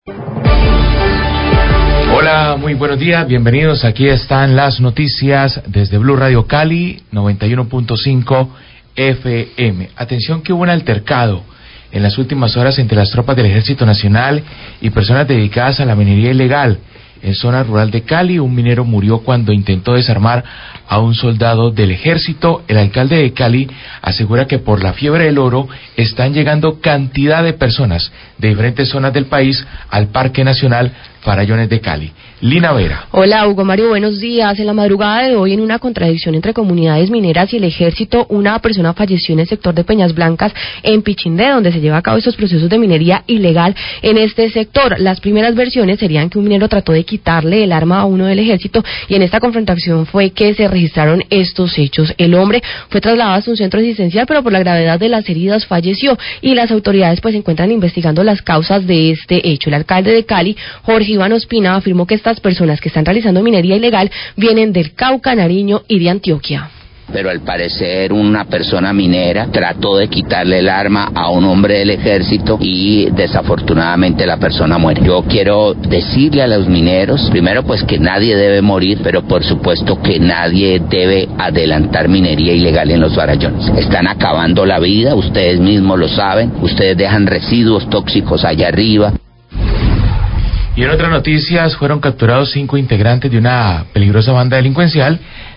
Radio
Un muerto y 4 heridos, así como la retención de varios militares, dejó el enfrentamiento entre mineros ilegales y miembros de la fuerzas militares en la verda de Peñas Blancas. Alcalde de Cali habla de lo sucedido y pide a los mineros parar su actividades que contaminan los ríos.